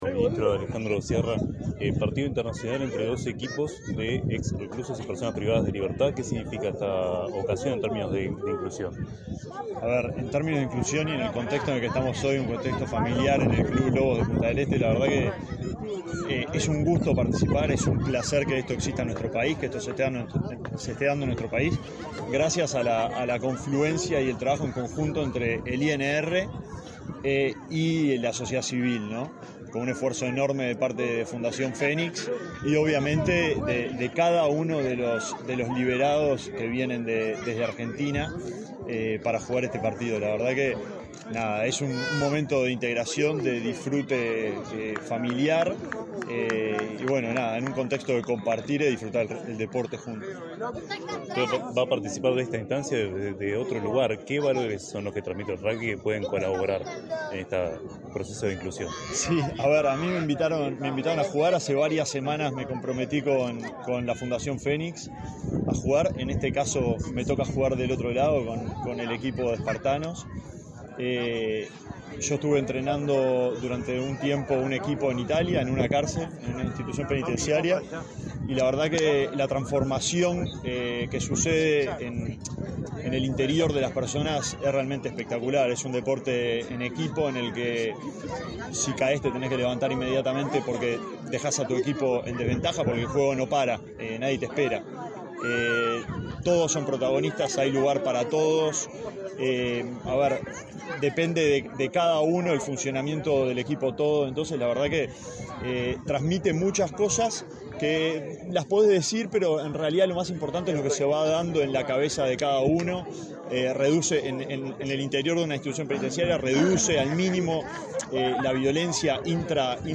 Entrevista al ministro de Desarrollo Social, Aejandro Sciarra
Entrevista al ministro de Desarrollo Social, Aejandro Sciarra 20/07/2024 Compartir Facebook X Copiar enlace WhatsApp LinkedIn El presidente de la República, Luis Lacalle Pou, asistió, este 20 de julio, al partido preliminar de rugby entre Fénix y Espartanos, conformados por exreclusos de Uruguay y Argentina. Tras el evento, el ministro de Desarrollo Social, Alejandro Sciarra, realizó declaraciones a Comunicación Presidencial.